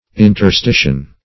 Search Result for " interstition" : The Collaborative International Dictionary of English v.0.48: Interstition \In`ter*sti"tion\, n. An intervening period of time; interval.